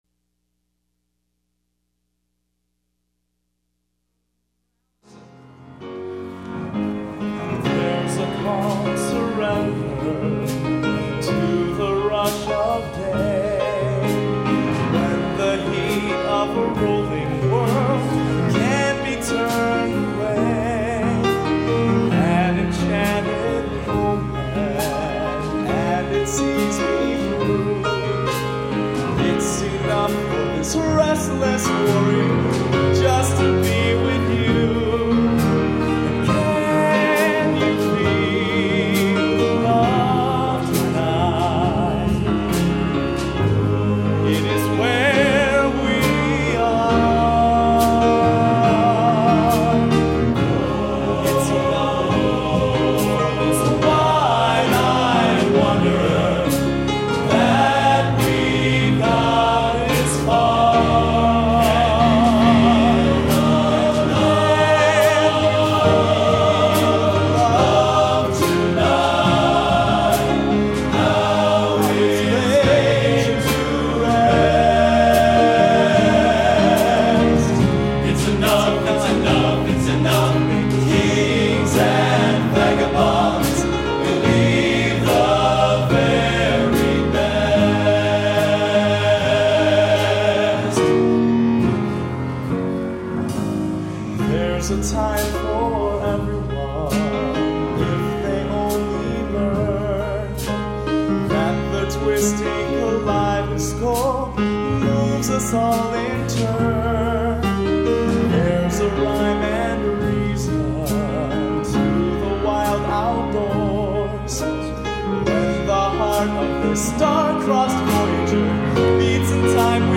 Genre: Cinema | Type: